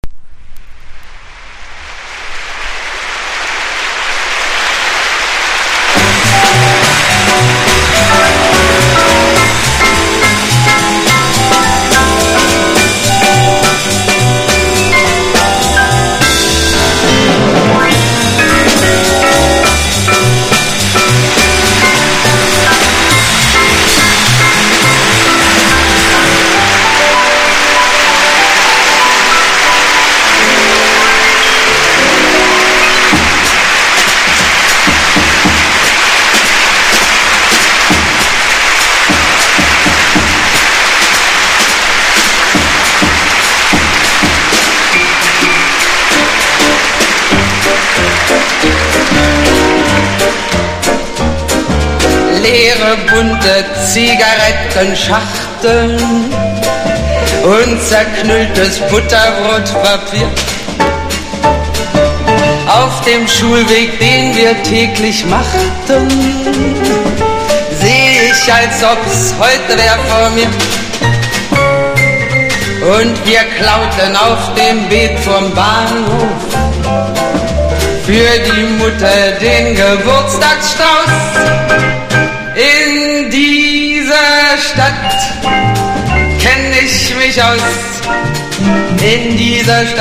1. VOCAL JAZZ >
ヨーロッパを中心に絶大な人気を誇ったドイツの女優／シンガーの人気ライブ盤！！